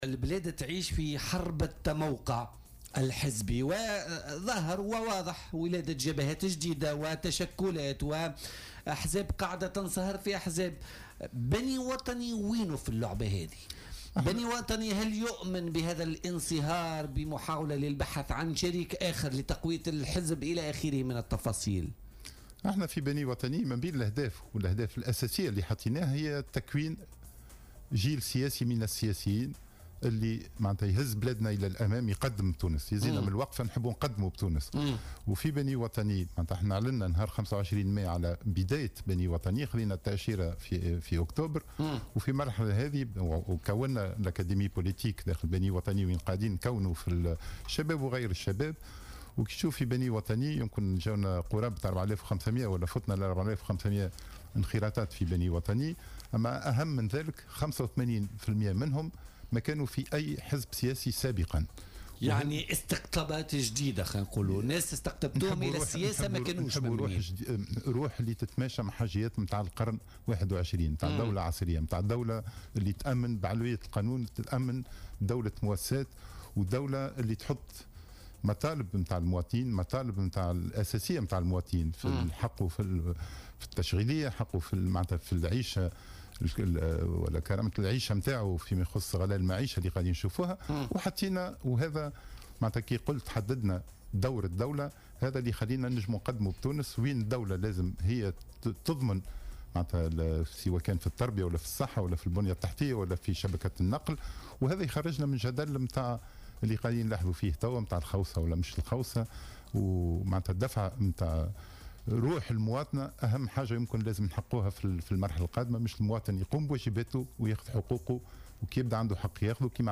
أكد رئيس حزب بني وطني سعيد العايدي ضيف بولتيكا اليوم الأربعاء 29 نوفمبر 2017 أن هدف حزبه الحاصل على التأشيرة مؤخرا هو تكوين جيل جديد من السياسيين يدفع بتونس إلى الأمام.